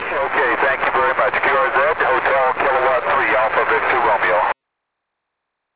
For this pourpose I have tried to record the signals from 100 countries (for now) as were heard on my rig; some of them are, of course, low in quality because of the weak signal (not always you have the dx at 59 level!), but the majority of them is really good.
Equipments: RTX: Kenwood TS680S, Antenna 6 el Yagi Create, SoundBlaster 16.